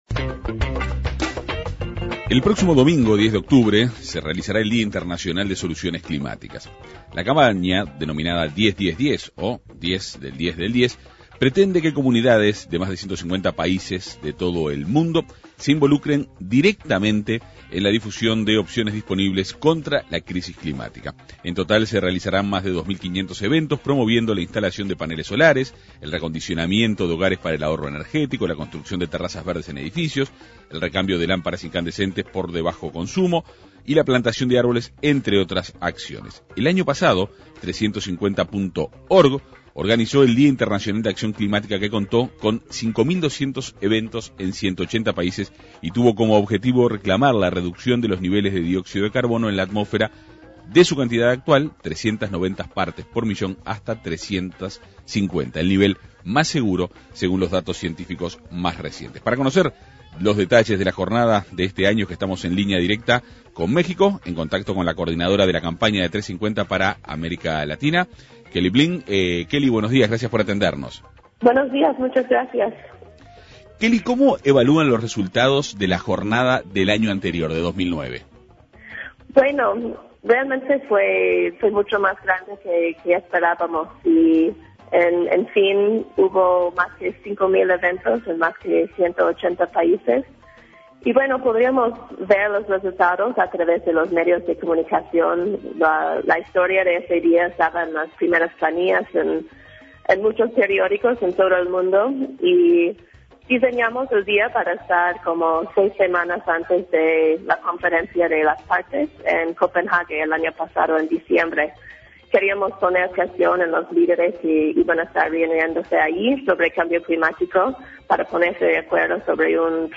también fue entrevistada.